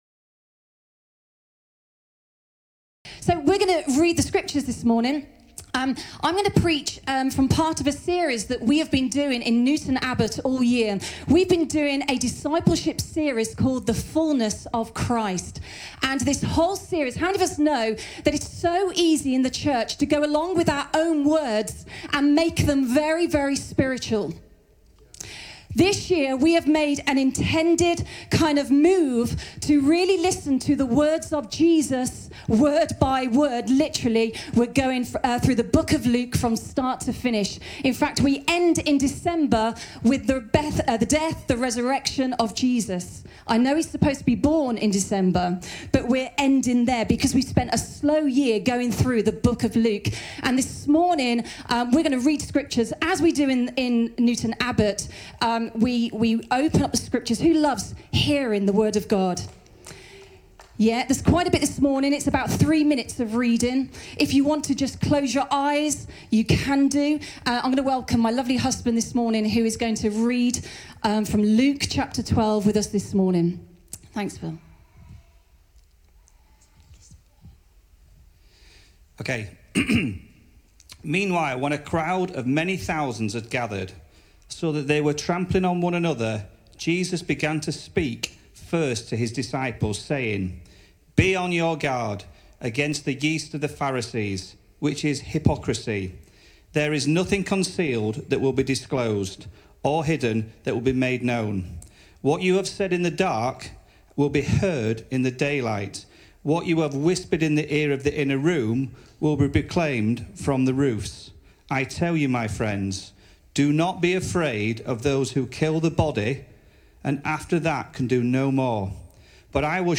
Sunday Messages